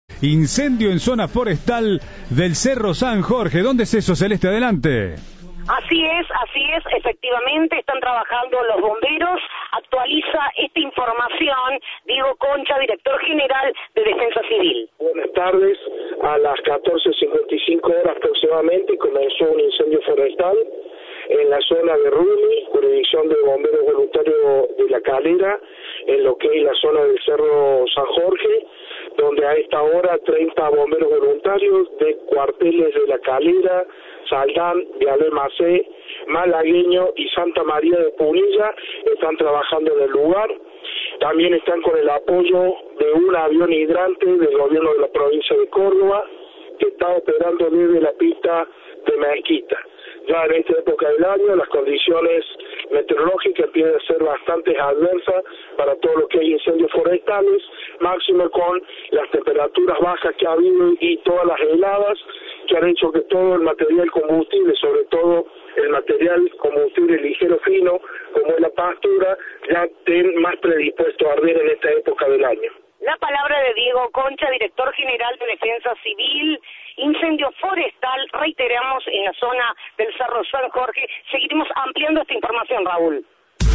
El director de Defensa Civil de Córdoba, Diego Concha, precisó a Cadena 3 que se registra en cercanías a la localidad de La Calera y que varias dotaciones de la región hacen guardia de cenizas.
Informe